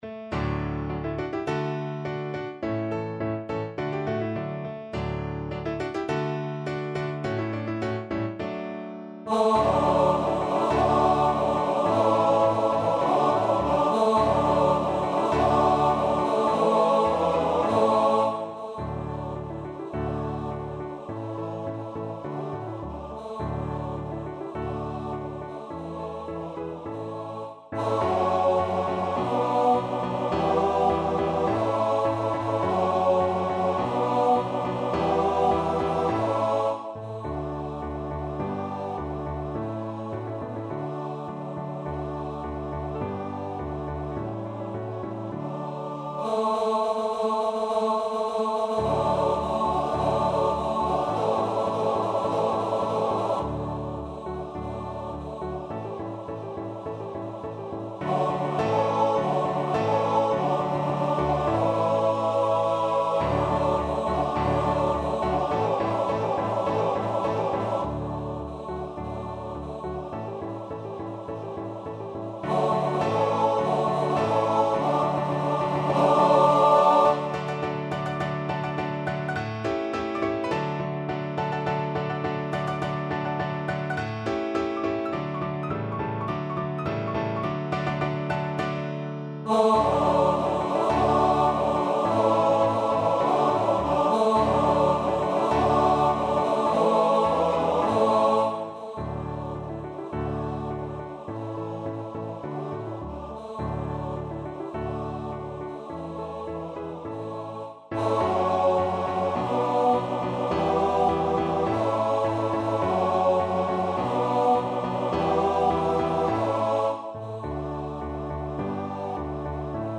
Free Sheet music for Choir (TTBB)
Tenor 1Tenor 2Bass 1Bass 2
2/4 (View more 2/4 Music)
D major (Sounding Pitch) (View more D major Music for Choir )
~ = 100 Molto vivace =104
Classical (View more Classical Choir Music)